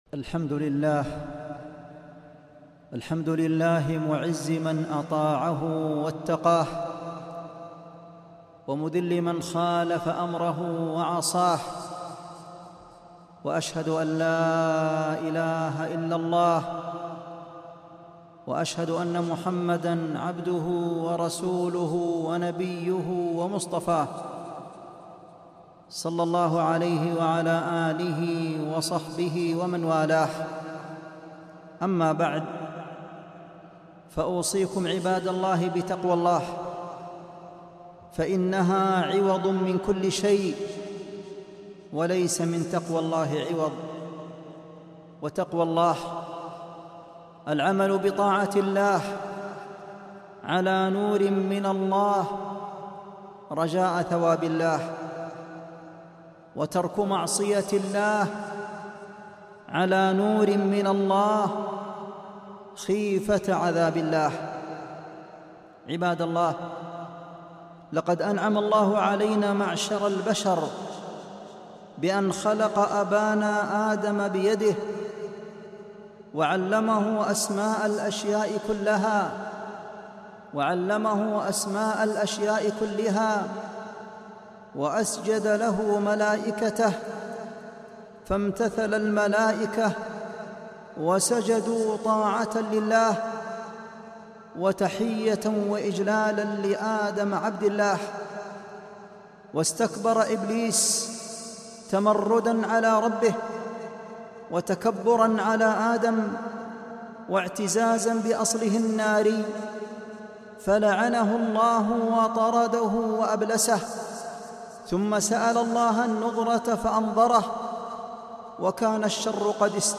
خطبة - النهي عن اتباع خطوات الشيطان